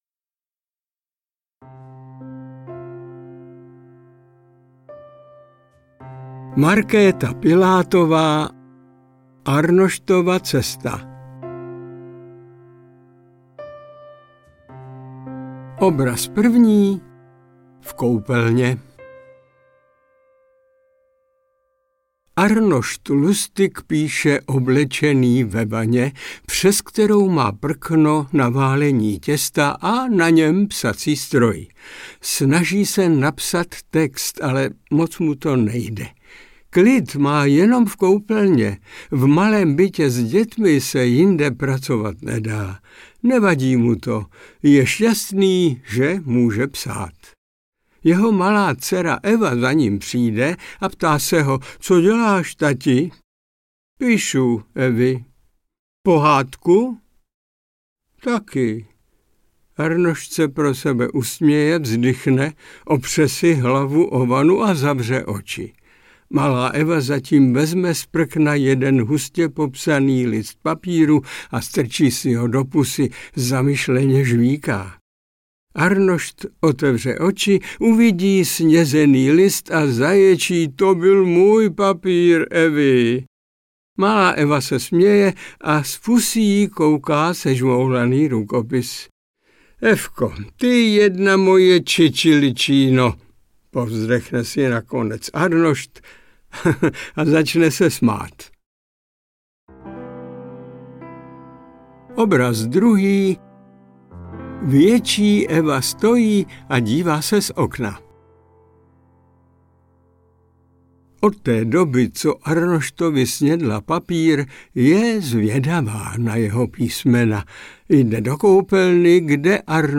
Arnoštova cesta audiokniha
Ukázka z knihy
Audiokniha je koncipována jako jejich dialog o cestě životem.
• InterpretMiloň Čepelka